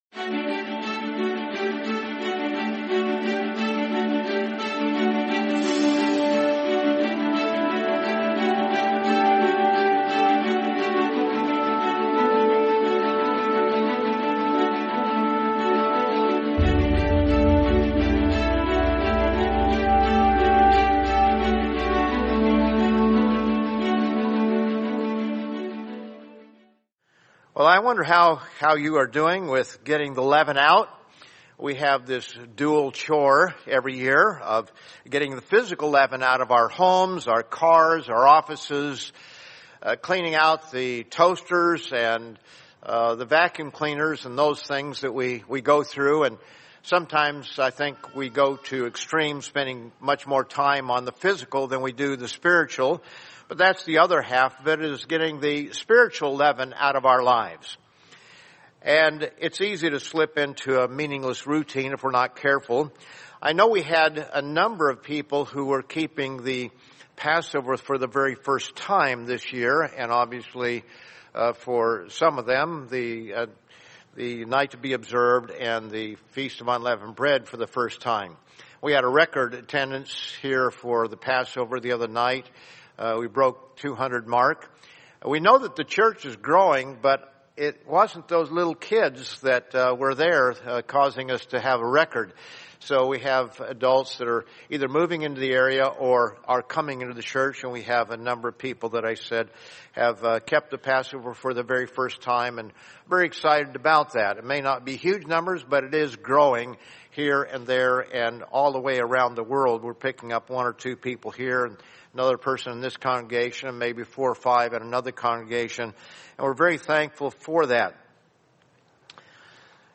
Deleavening an Uncircumcised Heart | Sermon | LCG Members